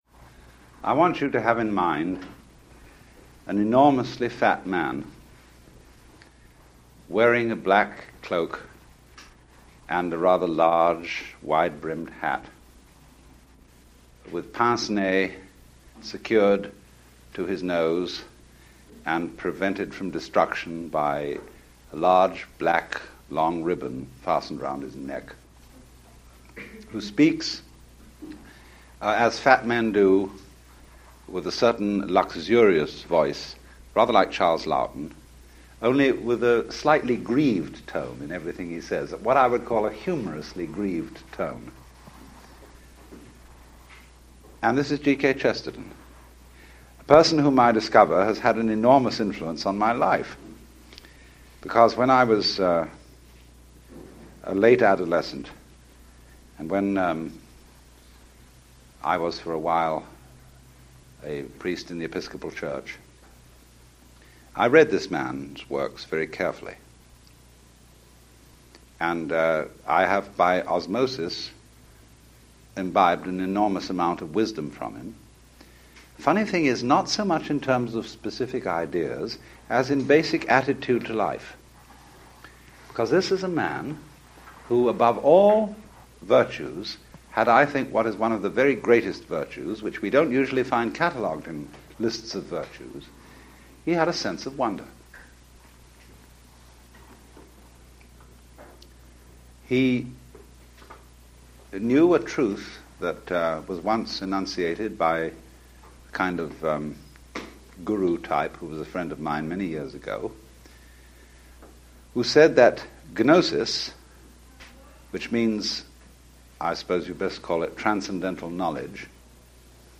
Alan Watts – Early Radio Talks – 09 – G.K. Chesterton – Sleepy Tooth Dental
Alan-Watts-Early-Radio-Talks-09-G.K.-Chesterton.mp3